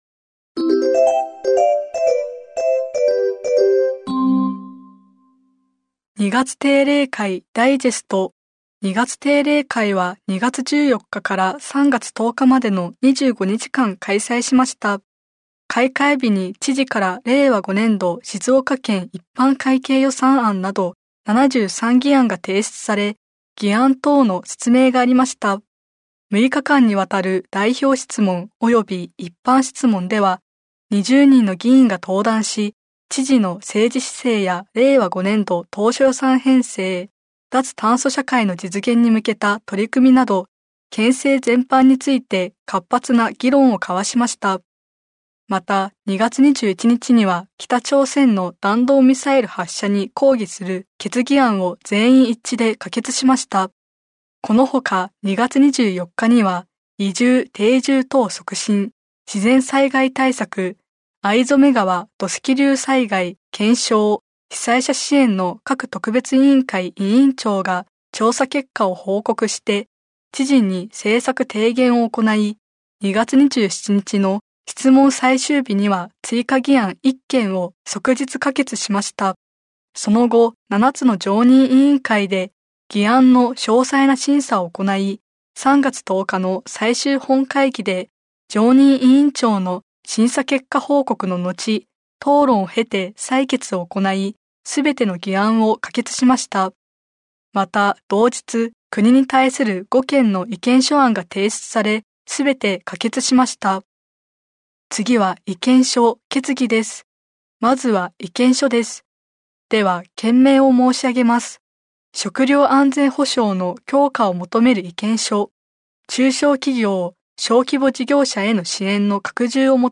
県議会だより第121号 音声版「声の県議会だより」